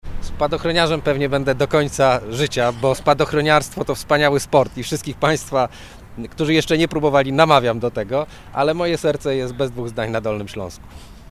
Jedna z dziennikarek na konferencji we Wrocławiu zapytała posła, czy czuje się bardziej Dolnoślązakiem, czy „spadochroniarzem”? Michał Dworczyk odpowiedział żartem.